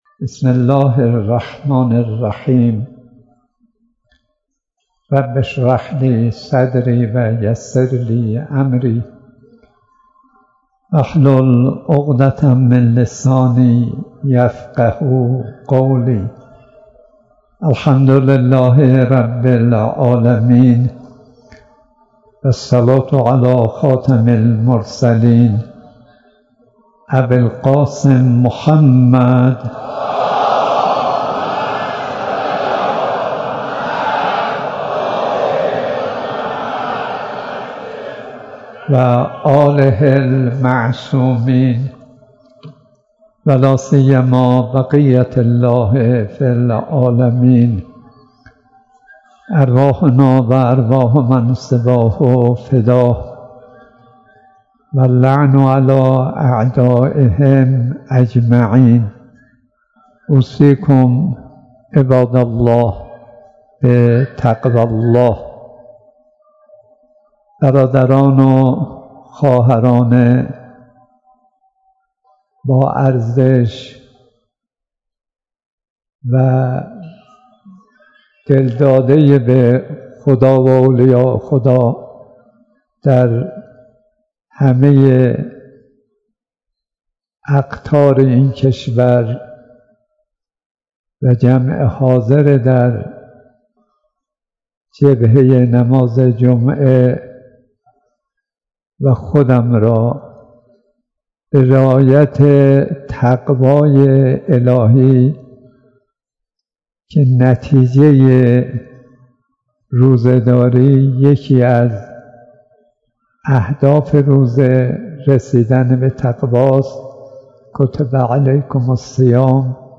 حاج آقا صدیقی در محضر استاد ۲۲۹ درس اخلاق آیت الله صدیقی؛ ۸ اردیبهشت ۱۴۰۳ در حال لود شدن فایل های صوتی...